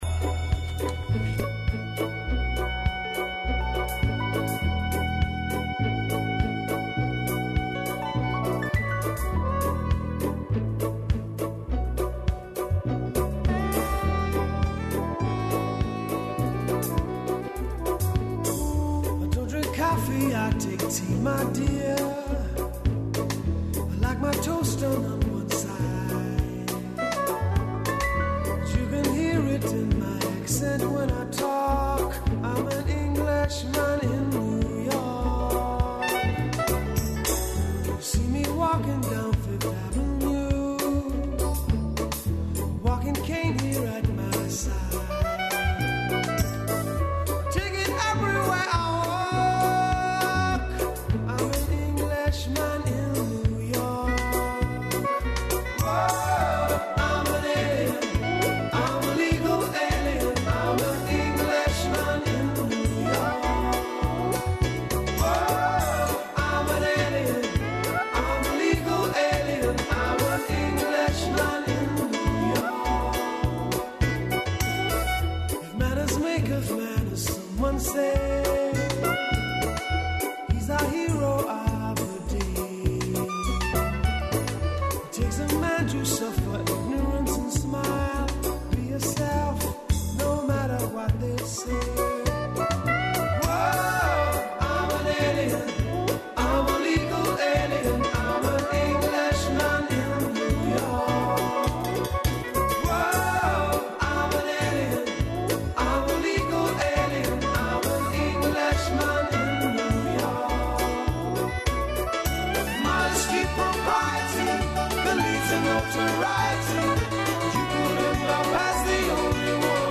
Јелка, украси и лампице су у студију, као и Ирвас и Деда Мраз.